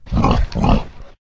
boar_idle_1.ogg